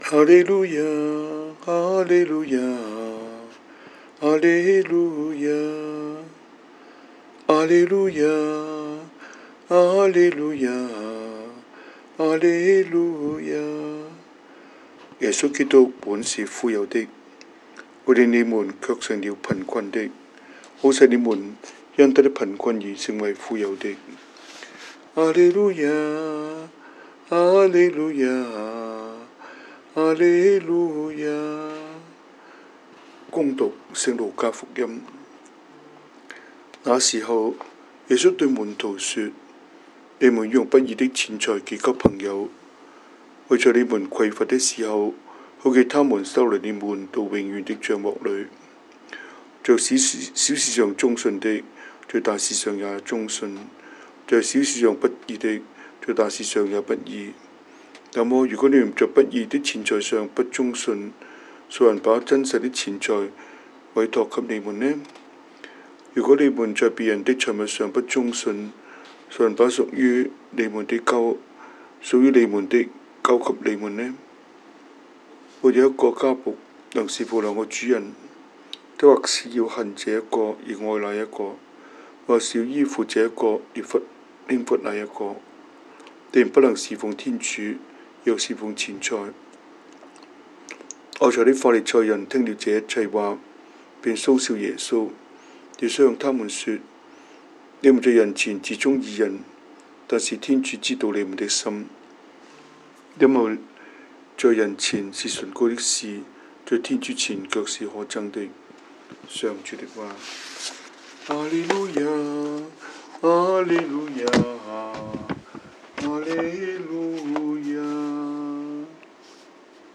Cantonese Homily, Eng Homily